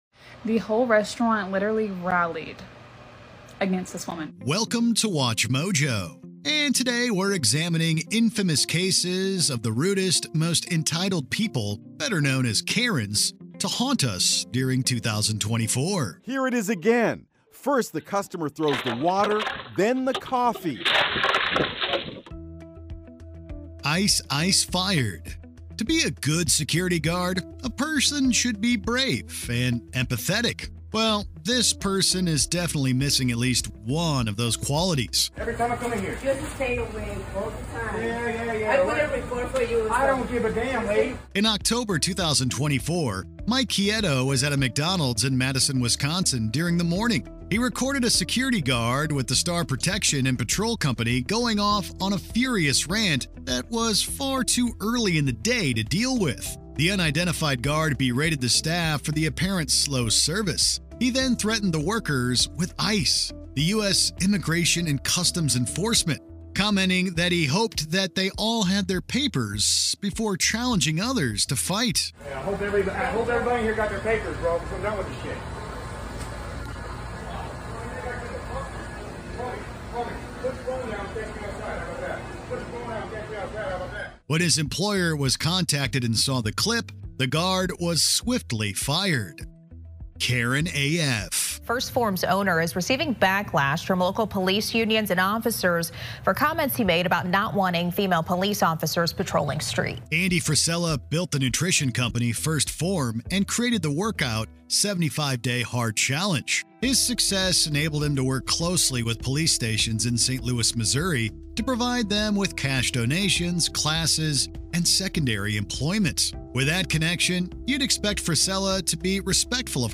English - USA and Canada
Middle Aged